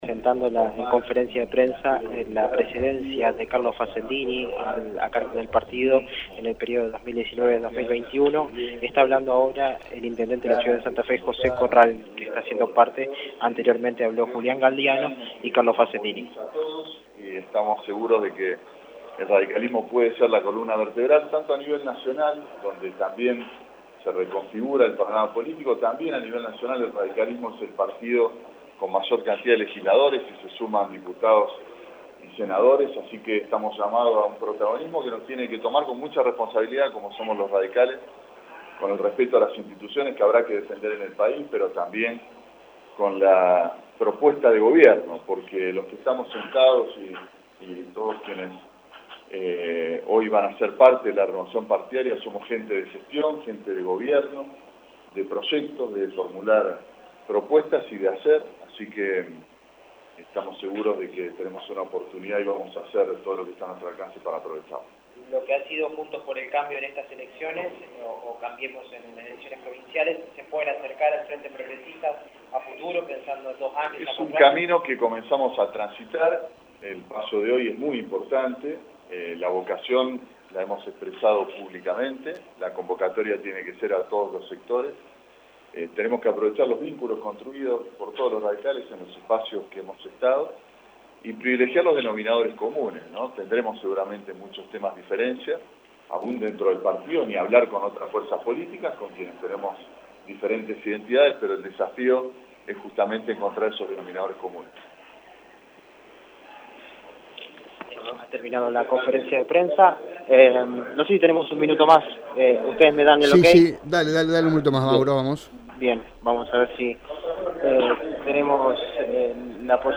Según el diálogo exclusivo que el vicegobernador saliente, Carlos Fascendini, mantuvo con Radio EME habrá discusiones que se darán hacia adentro del partido para poder generar confianza en la gente y solidez como oposición.